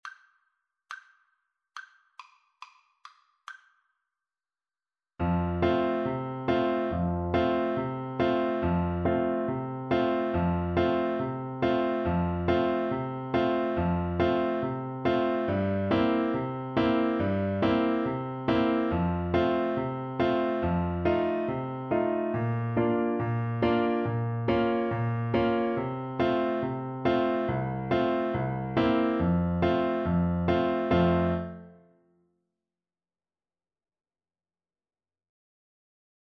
American gospel hymn.
Vivo =140